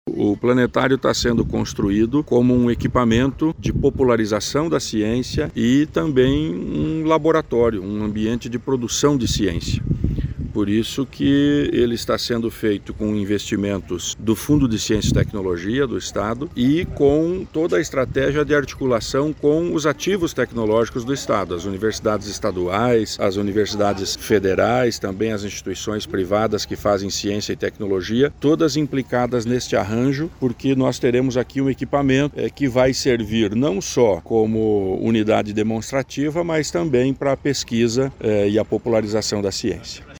Sonora do secretário Estadual da Ciência, Tecnologia e Ensino Superior, Aldo Bona, sobre a visita a obra do novo planetário do Parque da Ciência, em Pinhais